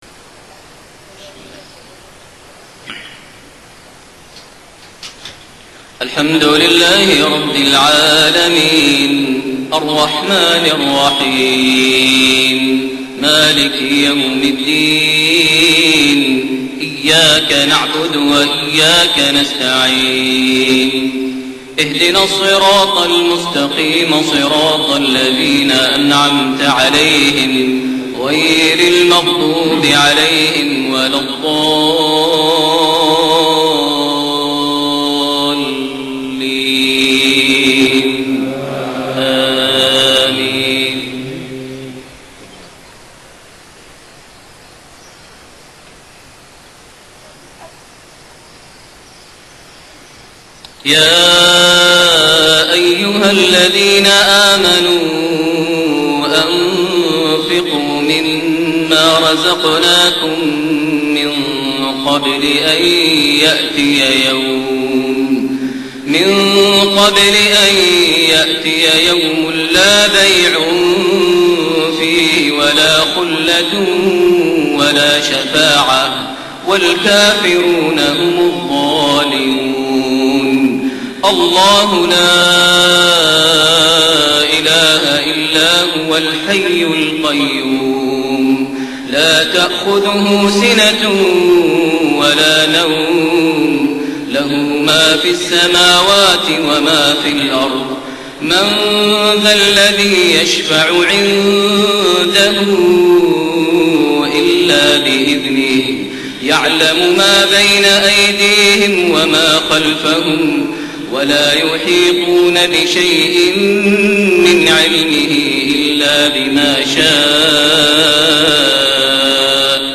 صلاة المغرب 3-6-1432 | من سورة البقرة 254-257 > 1432 هـ > الفروض - تلاوات ماهر المعيقلي